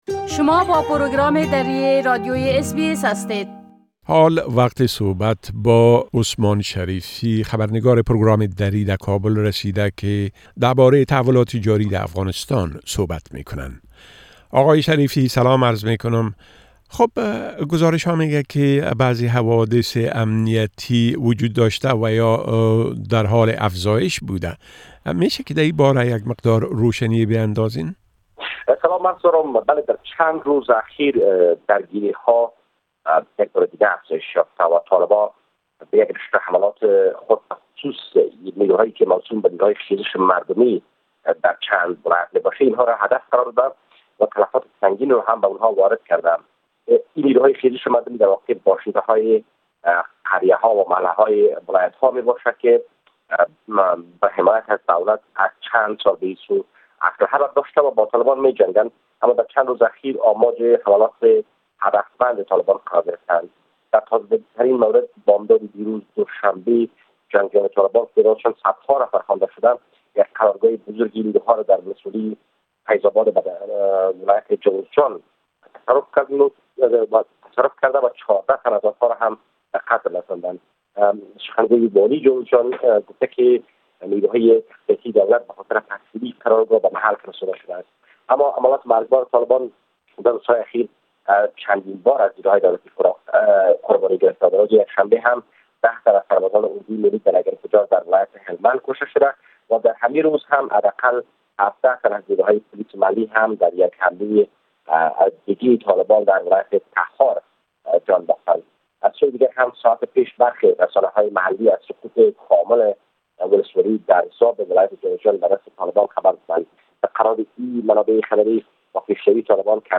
گرازش كامل خبرنگار ما در كابل بشمول اوضاع امنيتى٬ افزايش آلودگى هوا و تحولات مهم ديگر در افغانستان را در اينجا شنيده ميتوانيد.